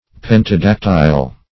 Search Result for " pentadactyle" : The Collaborative International Dictionary of English v.0.48: Pentadactyl \Pen`ta*dac"tyl\, Pentadactyle \Pen`ta*dac"tyle\, a. [Gr. pentada`ktylos with five fingers or toes.